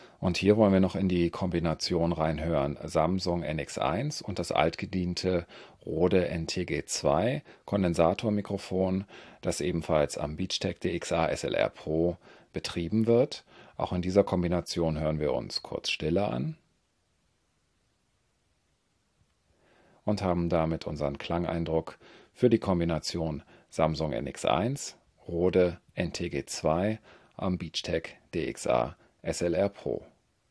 Wir haben bei den folgenden Tonbeispielen den internen Audio-Pegel der Samsung NX1 auf 1 gelassen und die jeweiligen externen Verstärker die Hauptverstärkerarbeit verrichten lassen.
Hier die normalisierte Version:
Samsung NX1 mit Beachtek DXA-SLR PRO und Rode NTG2 (Kondensator Richtmikro Phantomspannung)
SamsungNX1_NTG2Rode_norm.wav